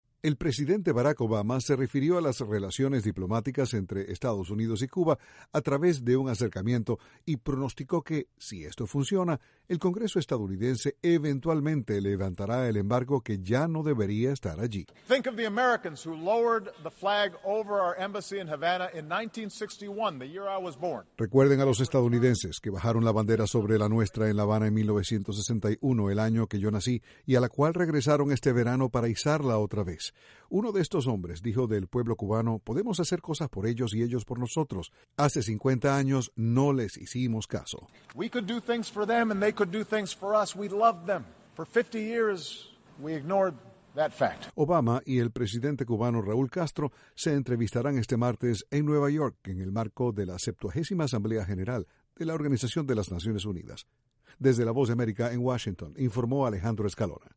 Según el presidente Barack Obama, el Congreso estadounidense eventualmente levantará el embargo a Cuba. Desde la Voz de América, Washington